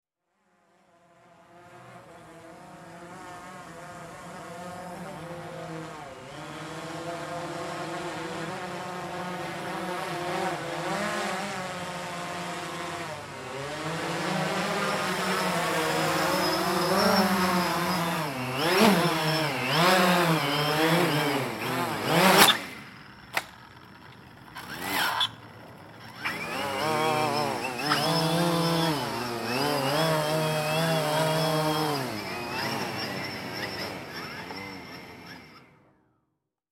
Звуки пропеллера
Шум пропеллеров квадрокоптера